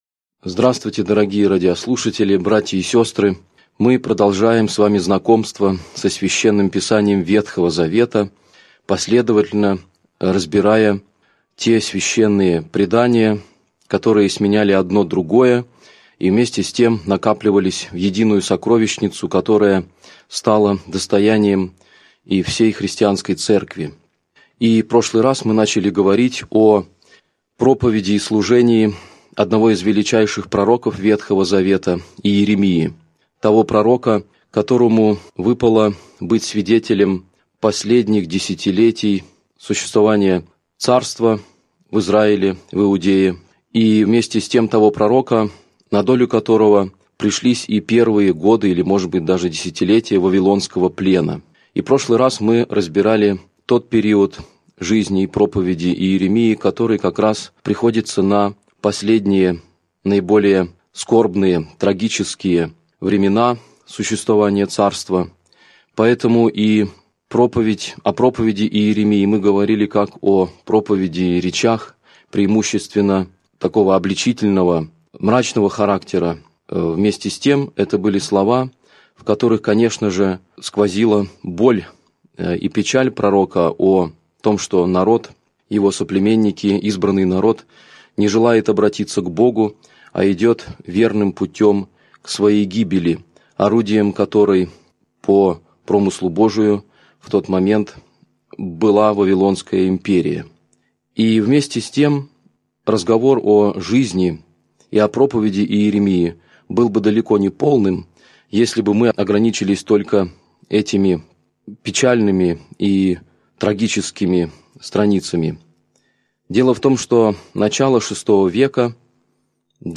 Аудиокнига Лекция 18. Пророк Иеремия (продолжение) | Библиотека аудиокниг